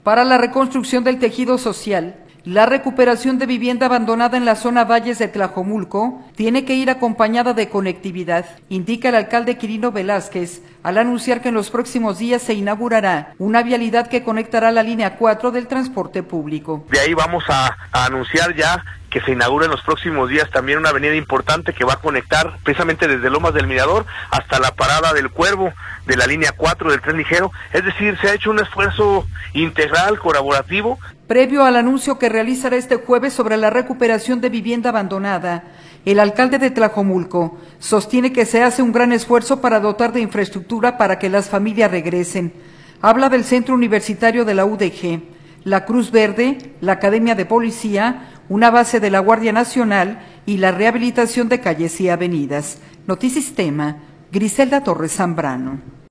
Para la reconstrucción del tejido social, la recuperación de vivienda abandonada en la zona Valles de Tlajomulco, tiene que ir acompañada de conectividad, indica el alcalde Quirino Velázquez al anunciar que en los próximos días se inaugurará una vialidad que conectará a […]